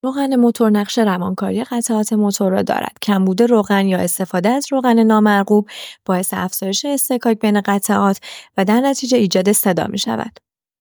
صدای تق‌تق یا چق‌چق از موتور ممکن است نشانه مشکل در سوپاپ‌ها باشد.
این صدا معمولاً به صورت تق‌تق، چق‌چق یا صدای تق‌تق‌زنی در هنگام کارکرد موتور به گوش می‌رسد.